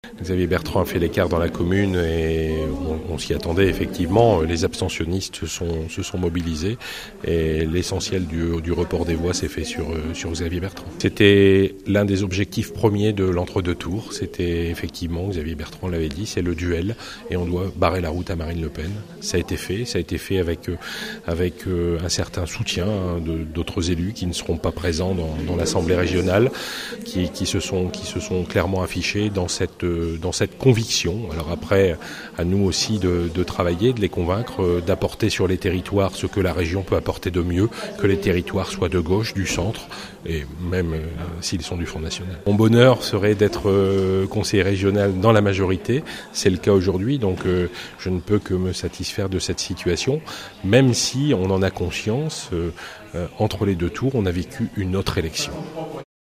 réaction de jean-François rapin, maire de Merlimont, réélu conseiller régional Les républicains